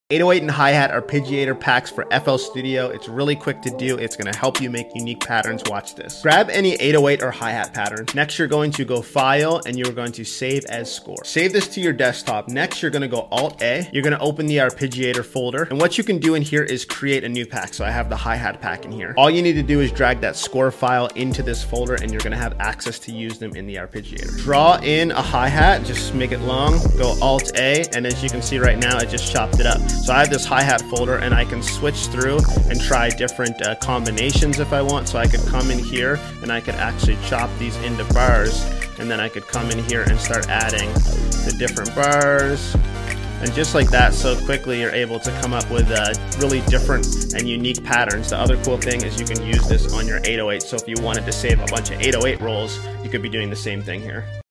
808 & Hi-Hat arpeggiator packs